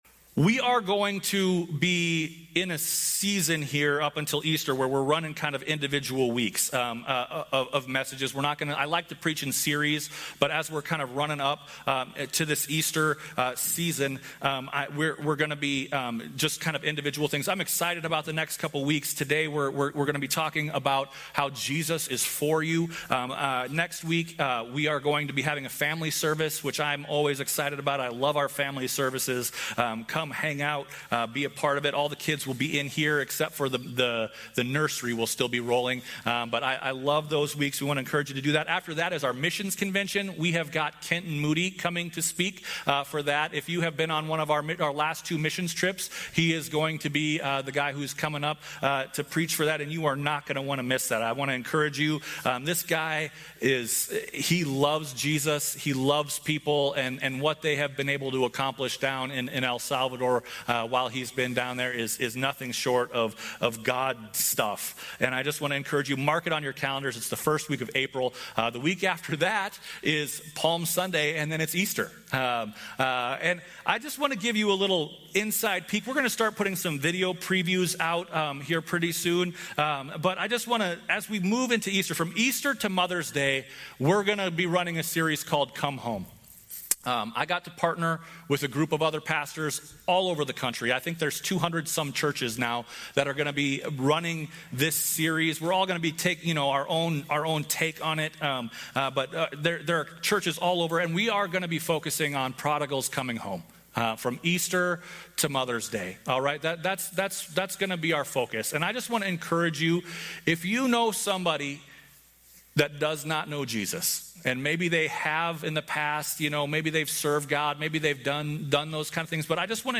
Some sermons that aren't in a normal series